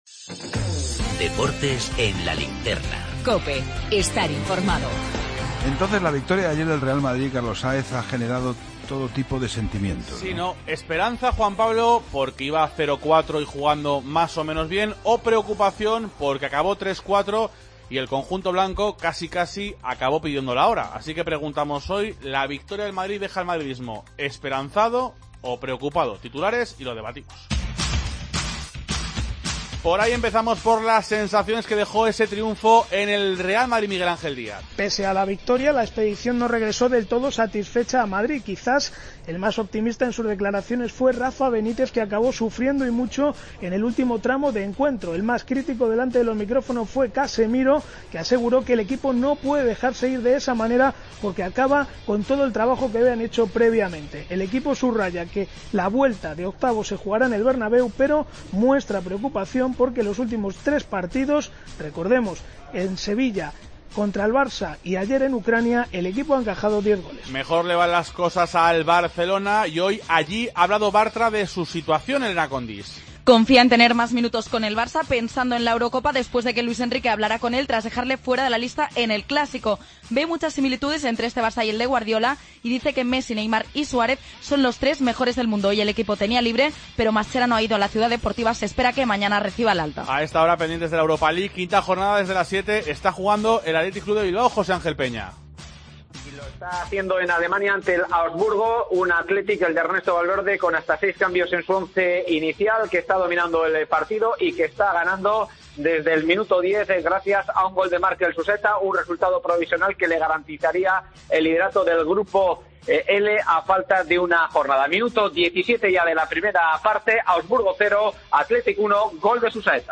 El debate